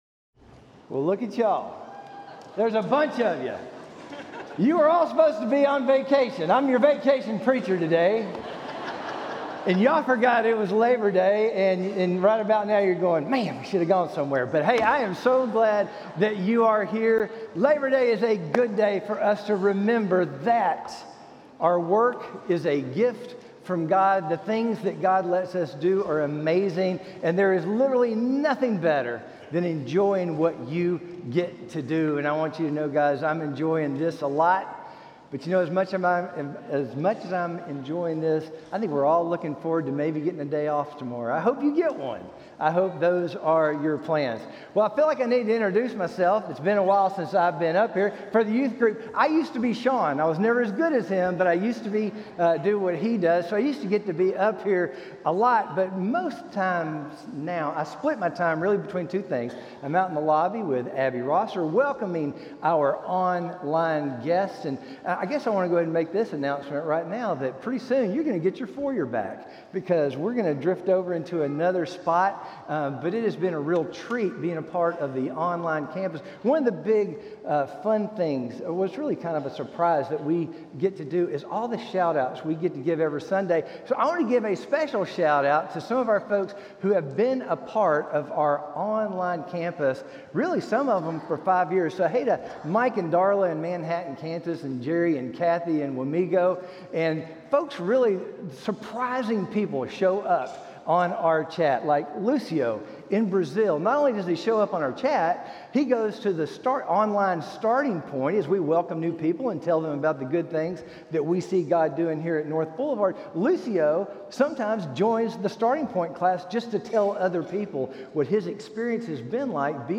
Title 00:00 / 01:04 All Sermons SERMON AUDIO GATHERING Audio download audio download video Download Video Video Also on Challenge Accepted!